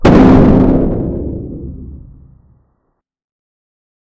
explosion3.ogg